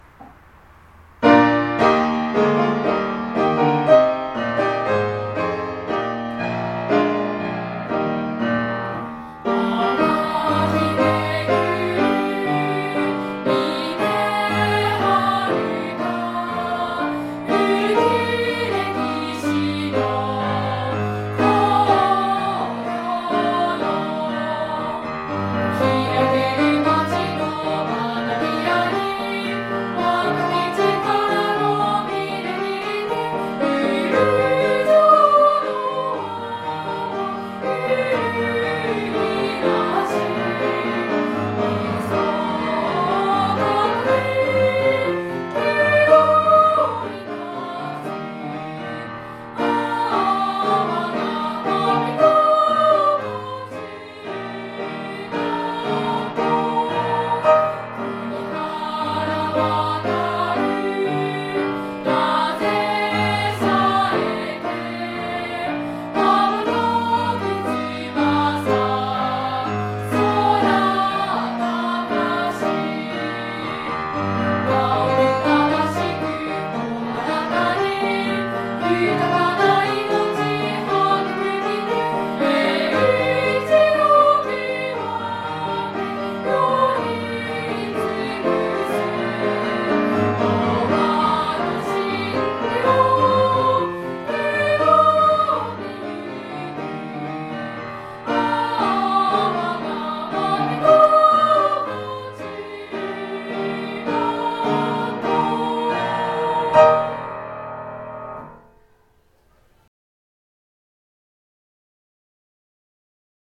mamigaokaschoolsong.mp3